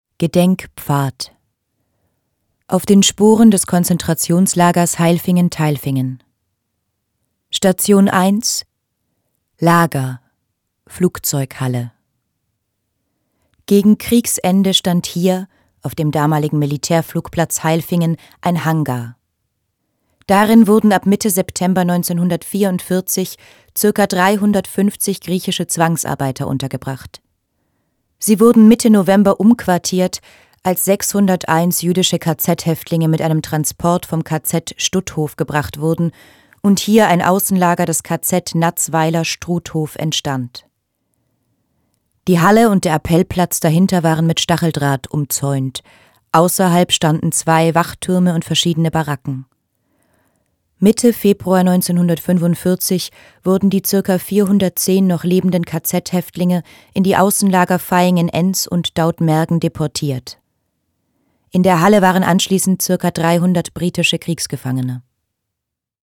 Audioguide Basisinfo
hohe Audioqualität